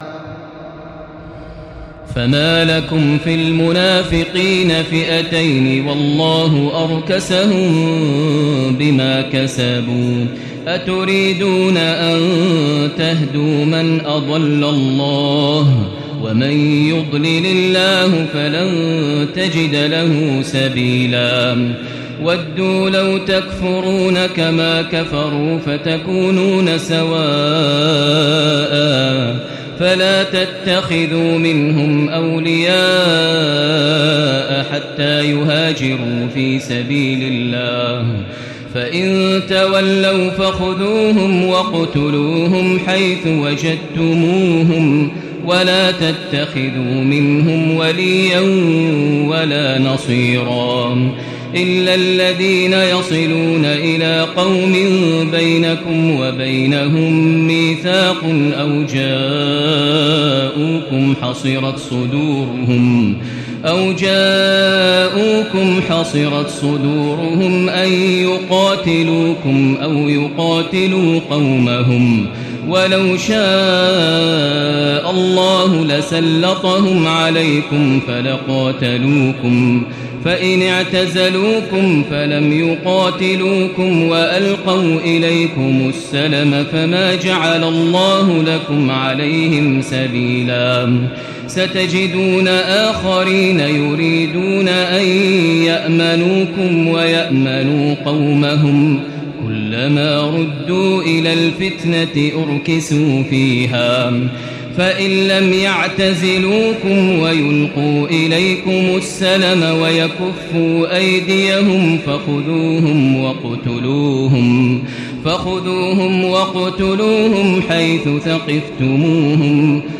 تراويح الليلة الخامسة رمضان 1436هـ من سورة النساء (88-162) Taraweeh 5 st night Ramadan 1436H from Surah An-Nisaa > تراويح الحرم المكي عام 1436 🕋 > التراويح - تلاوات الحرمين